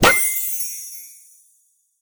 magic_pop_open_01.wav